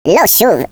Add hebrew voices